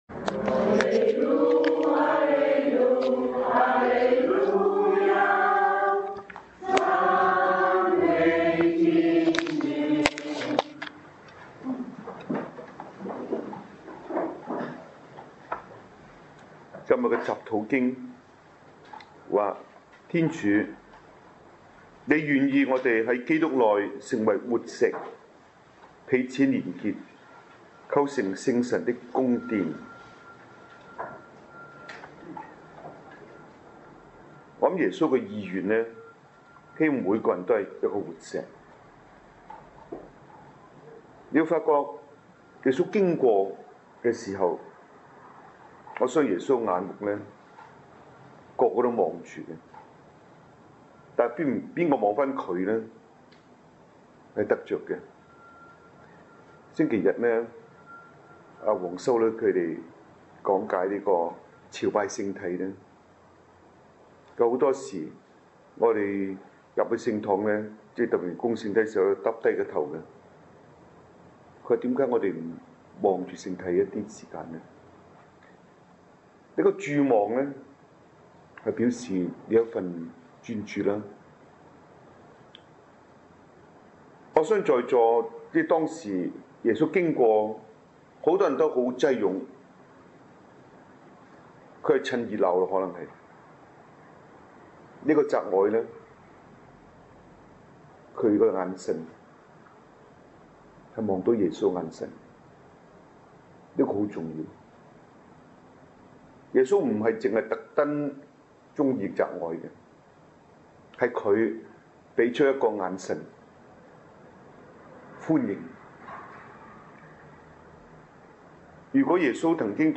Anniversary-of-Dedication-of-Cathedral_2011.MP3